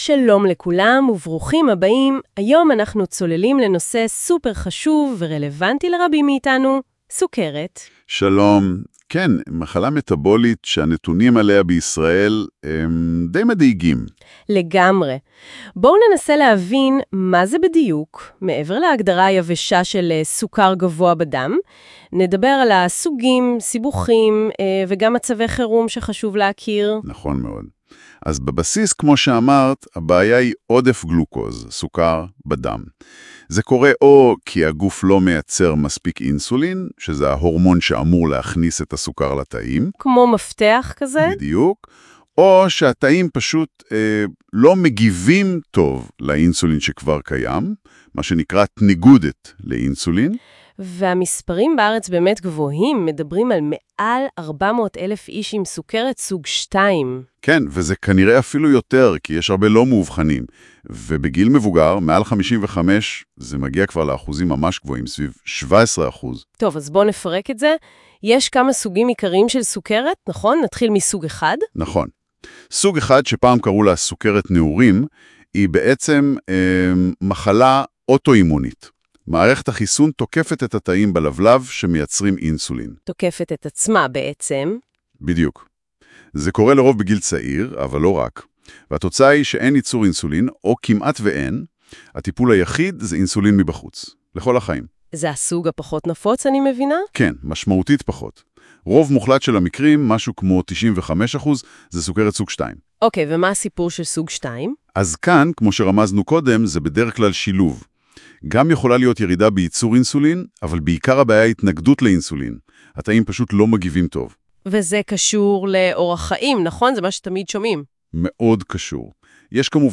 את ההסכת (פודקאסט) מעבירים מנחה גבר ומנחה אישה, לתשומת לבכם, טרום ההאזנה.